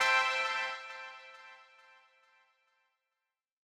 KIN Stab C3.wav